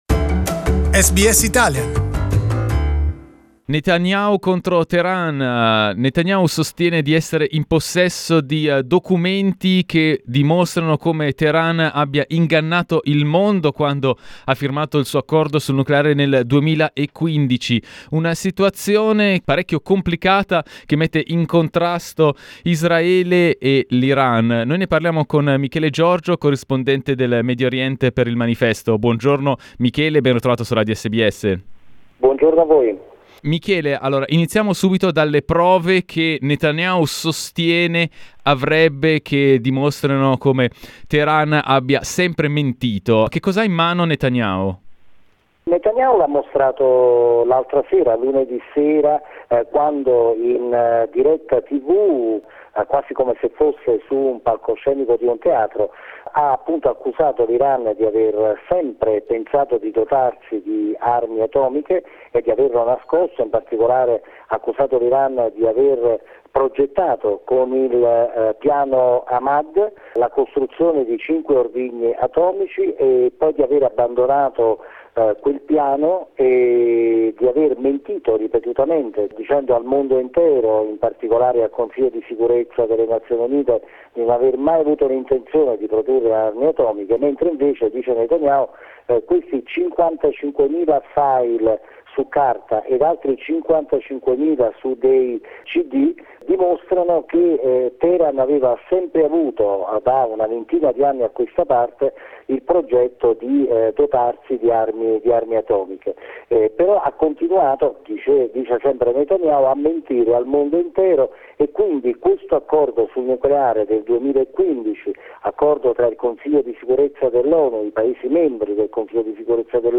Abbiamo parlato della tensione tra i due paesi con il corrispondente per il Medio Oriente